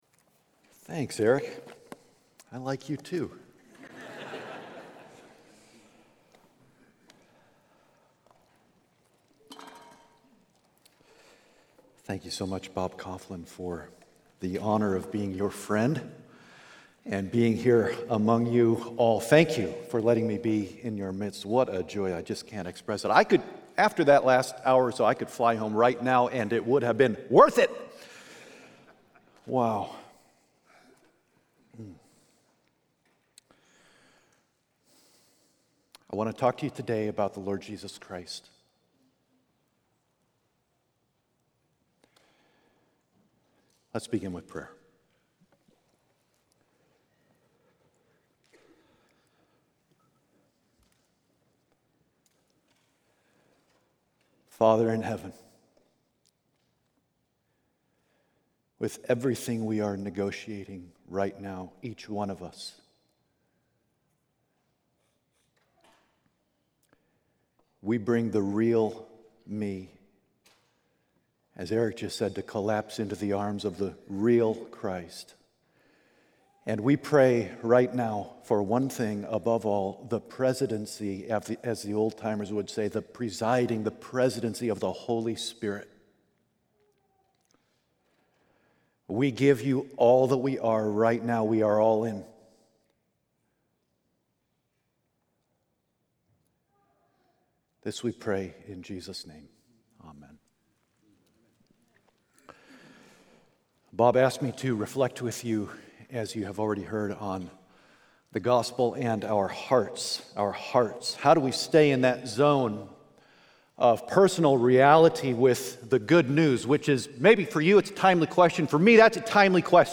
Conference Messages